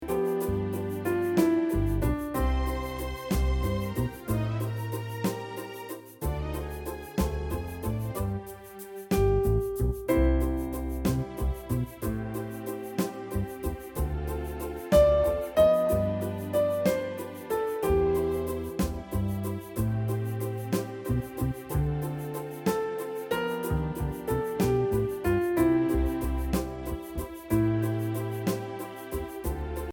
Voicing: Piano Method